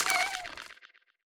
Click Back (4).wav